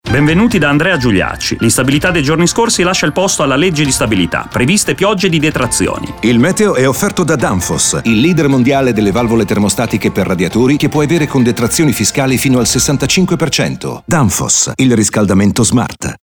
La campagna è in onda in questi giorni su RTL e sarà trasmessa fino al 24 settembre.